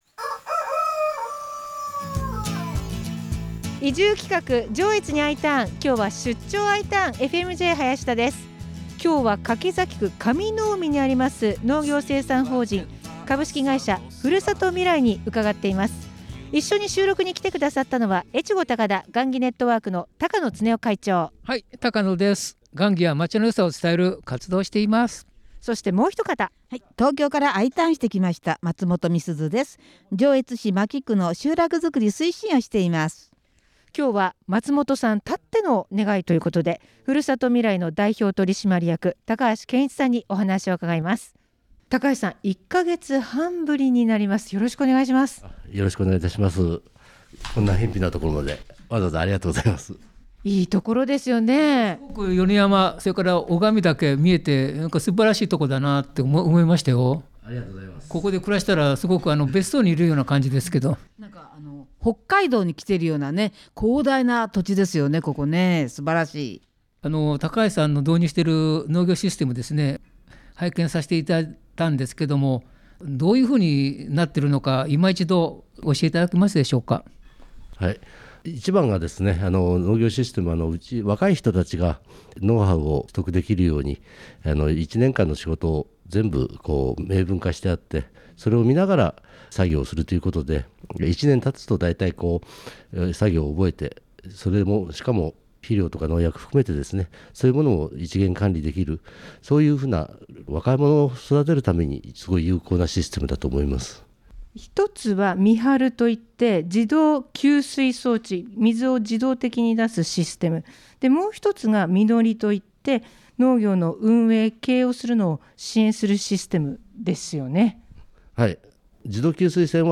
今回は、出張愛ターン(*'▽')🎤(*´▽｀*)('▽'*)